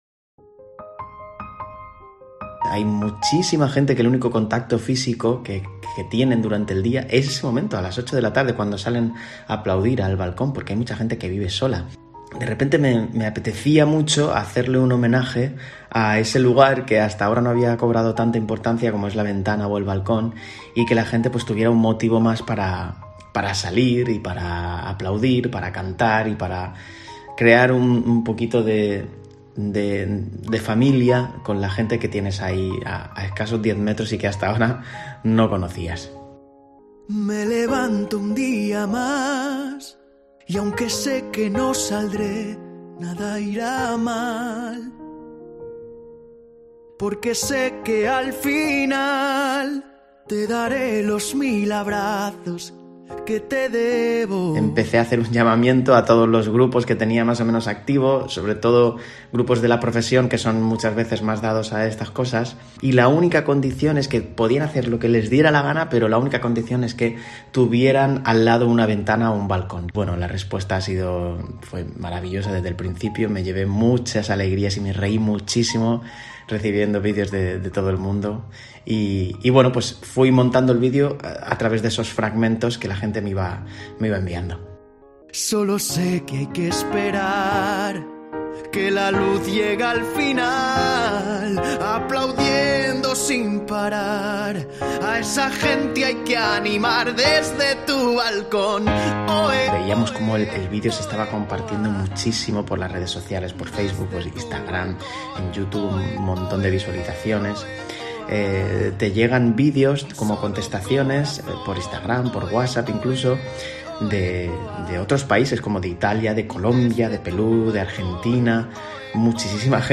Entrevista desde tu balcon